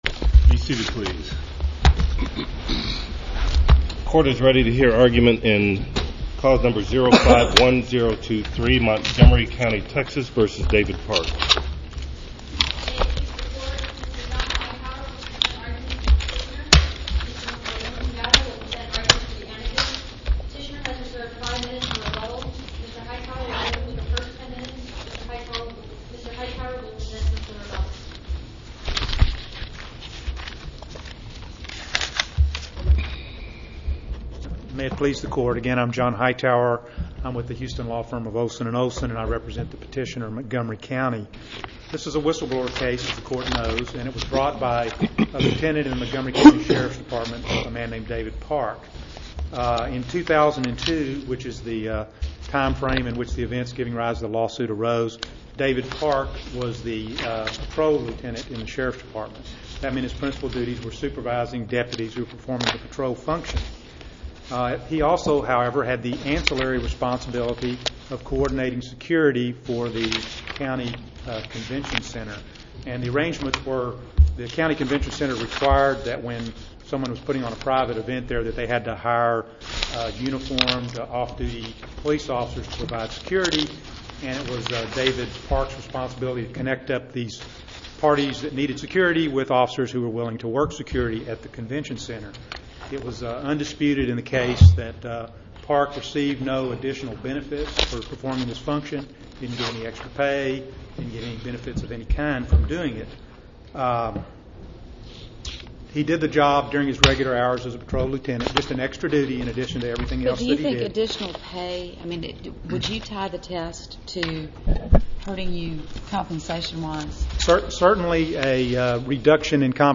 Case No. 05-0006 Oral Arguments Audio (MP3)